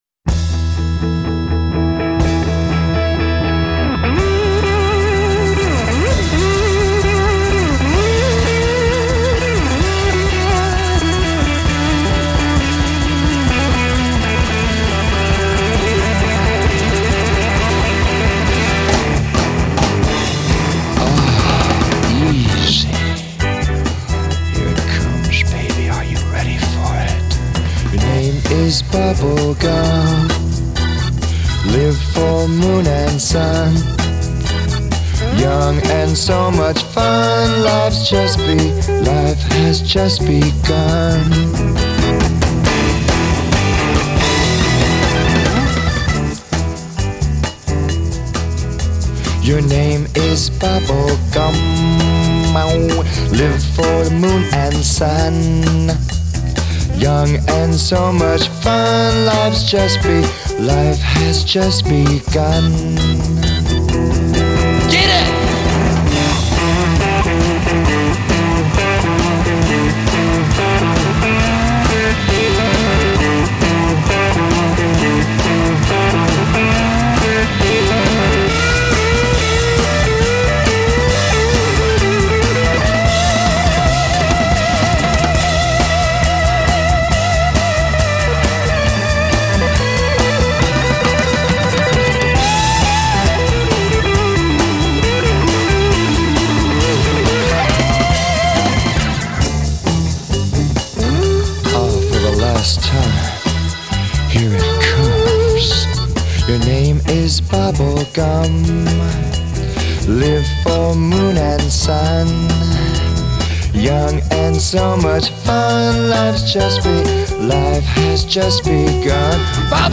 Very of its time.